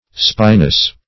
spynace - definition of spynace - synonyms, pronunciation, spelling from Free Dictionary
Spynace \Spy"nace\ (sp[imac]"n[asl]s; 48), Spyne \Spyne\